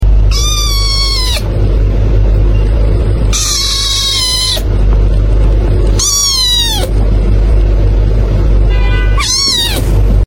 Raw smoky voiced cat vocals#fyp#kittensoftiktok#cutekitten#catlover#toocute#catvideo#kitty sound effects free download